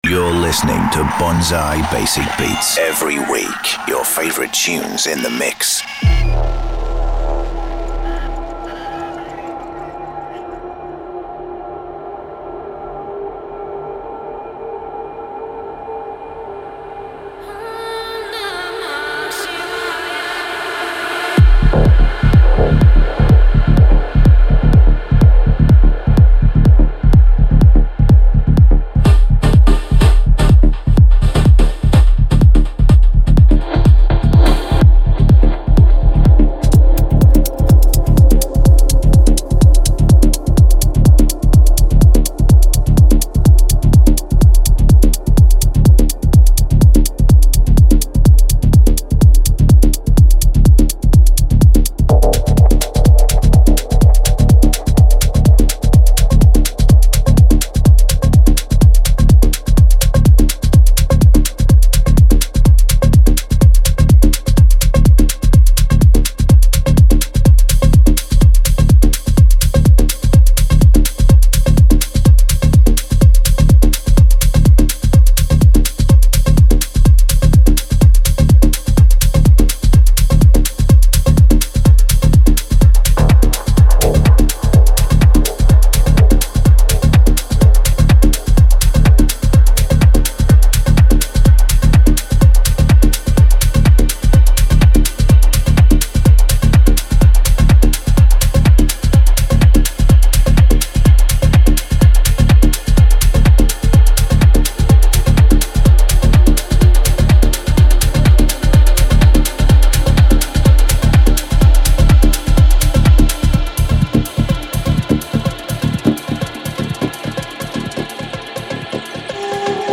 a journey across the spectrum of electronic sound